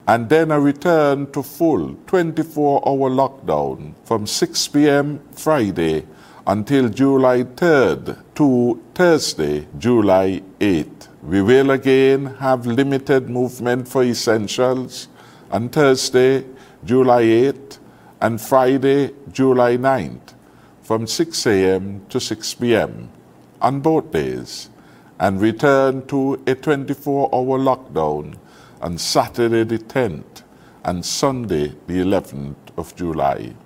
Prime Minister, Dr. Timothy Harris.